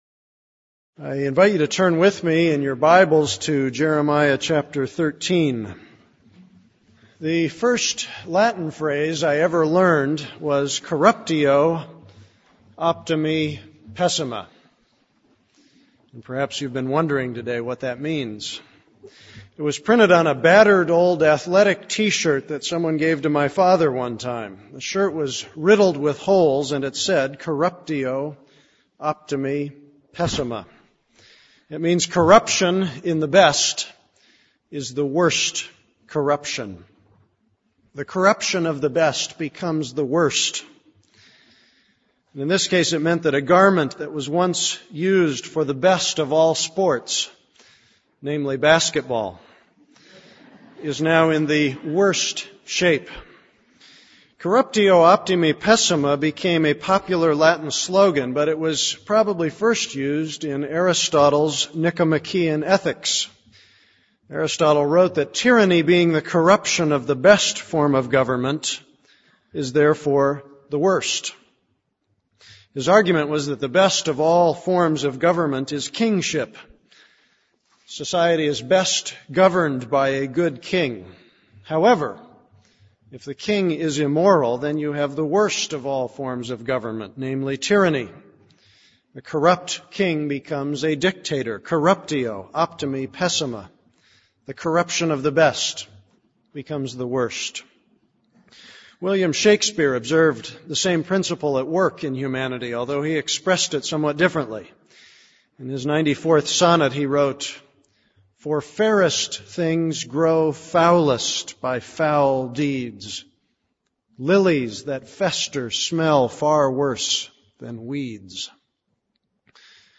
This is a sermon on Jeremiah 13:1-27.